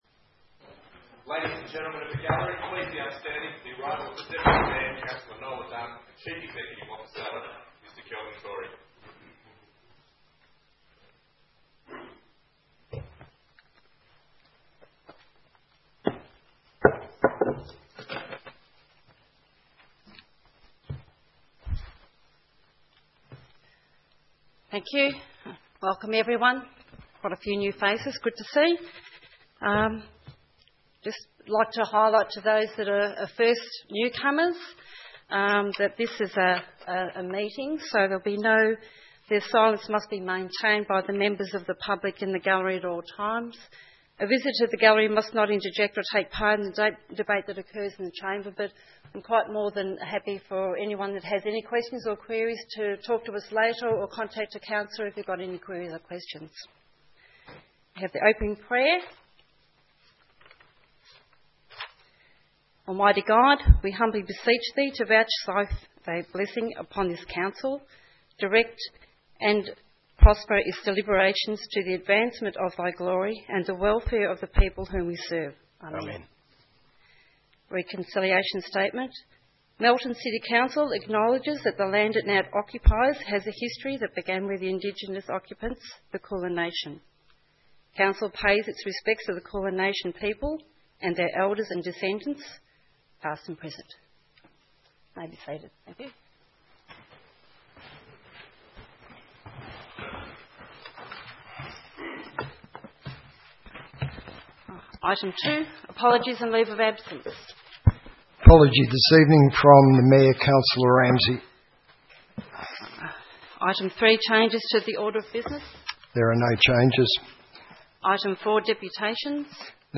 8 September 2015 - Ordinary Council Meeting